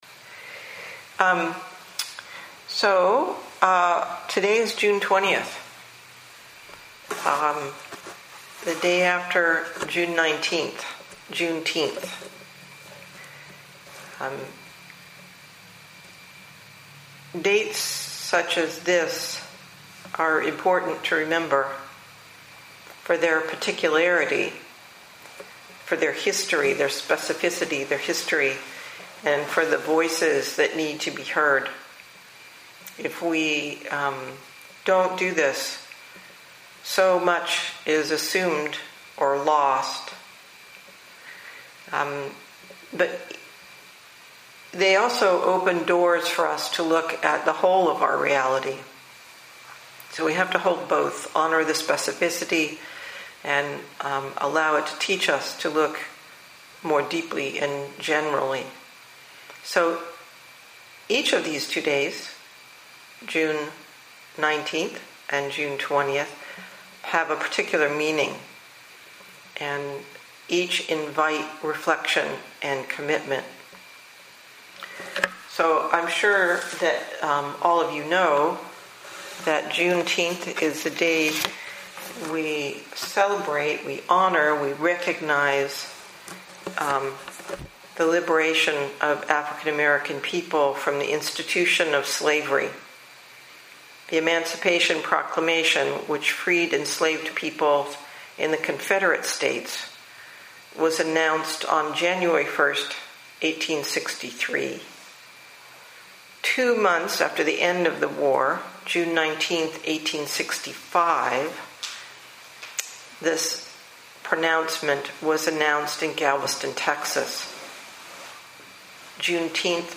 2020 in Dharma Talks